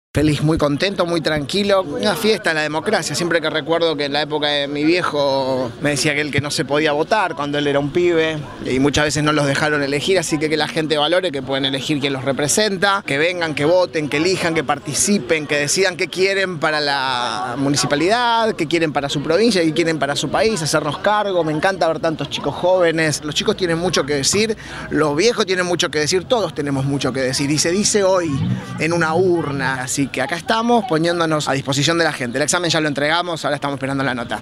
Escuchá la palabra del Intendente